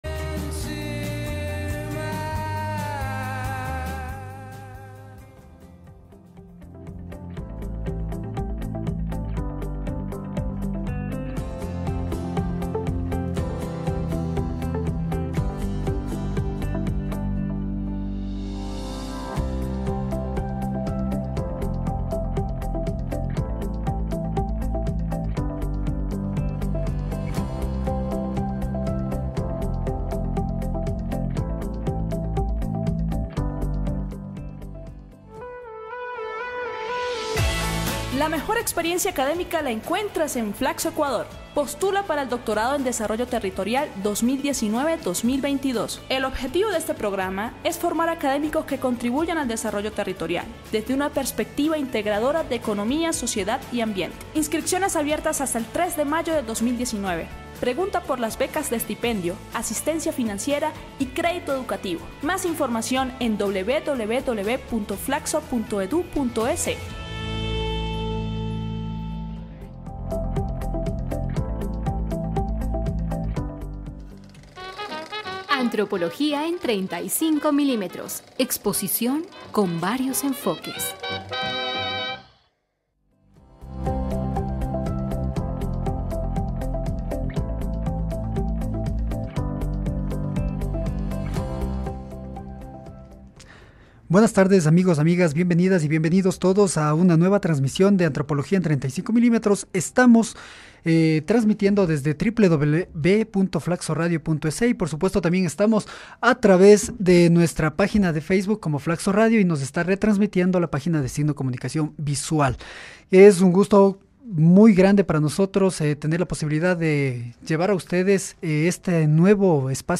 Audio - entrevista